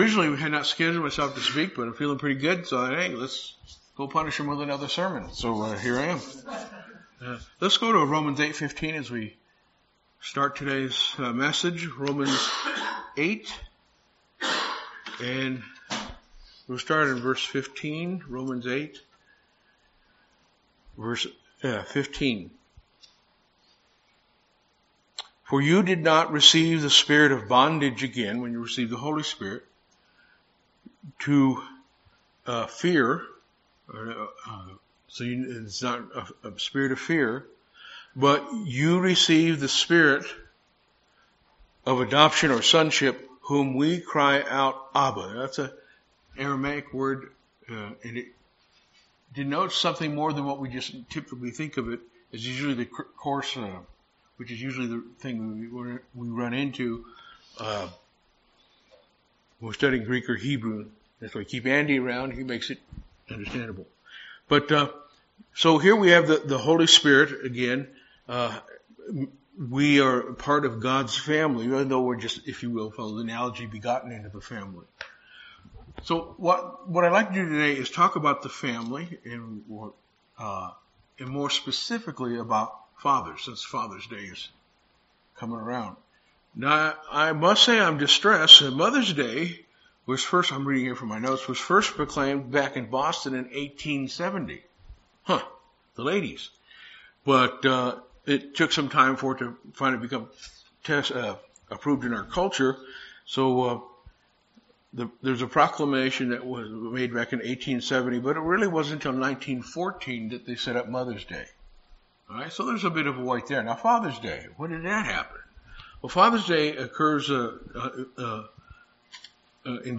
Father's Day Sermon